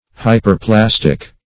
Search Result for " hyperplastic" : The Collaborative International Dictionary of English v.0.48: Hyperplastic \Hy`per*plas"tic\, a. 1.
hyperplastic.mp3